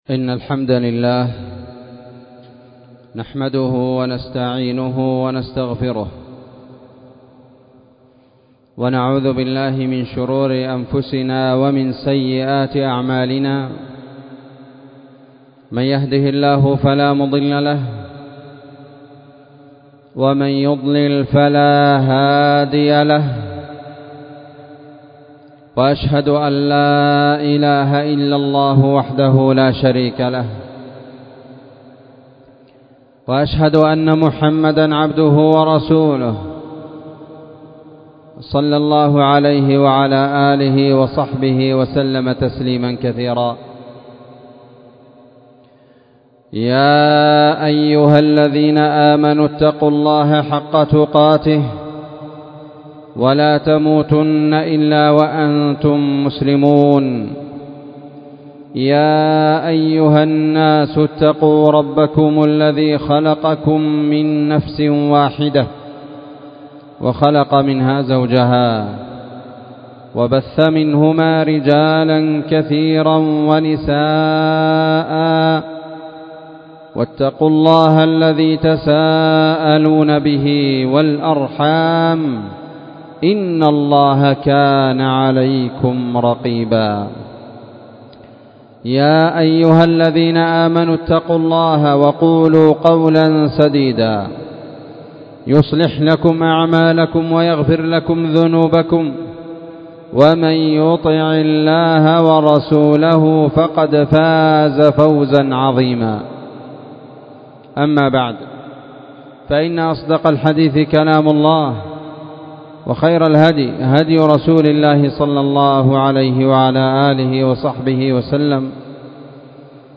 خطبة
والتي كانت في مسجد المجاهد- النسيرية- تعز